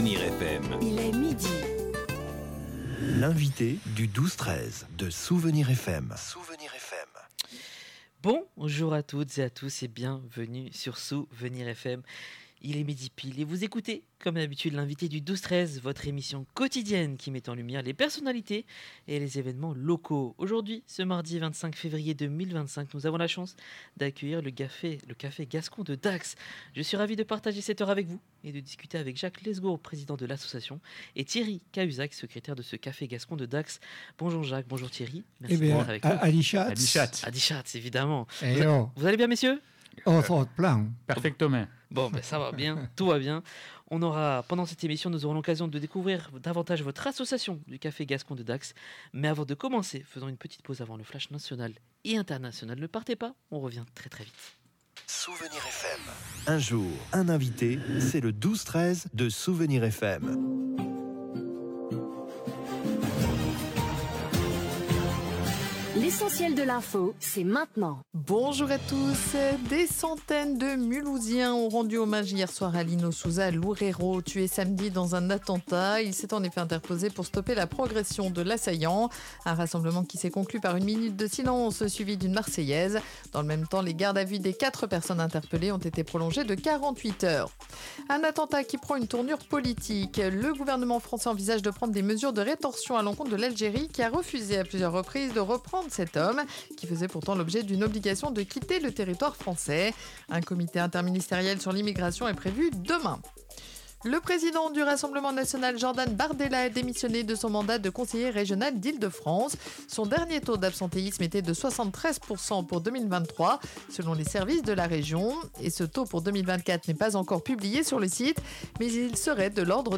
Le Café Gascon de Dax était présent dans nos studios ce midi, lors de l'émission L'invité du 12/13h.